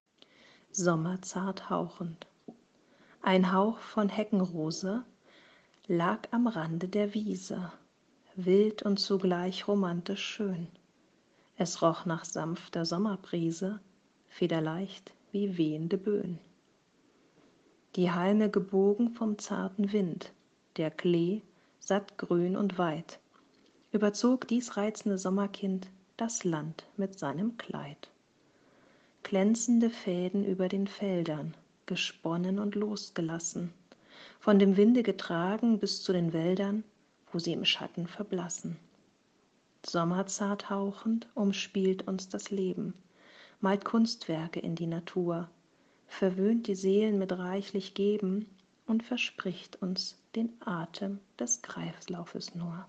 Ich lese auch vor
Meine Gedichte zum Hören